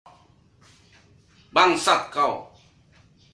Efek Suara Bangsat Kau meme
Kategori: Suara viral
efek-suara-bangsat-kau-meme-id-www_tiengdong_com.mp3